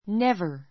never 小 A1 névə r ネ ヴァ 副詞 （ ⦣ 比較変化なし） 決して～ない , まだ一度も～ない ✓ POINT not （～ない） ＋ever （今までに, いつまでも） の意味で, 「強い打ち消し」を表す.